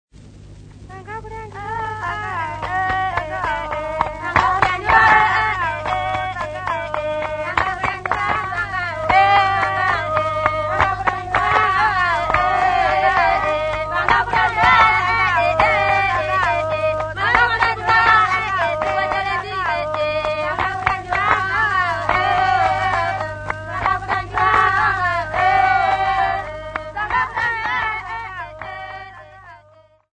Folk Music
Field recordings
Africa, Sub-Saharan
sound recording-musical
Indigenous music
96000Hz 24Bit Stereo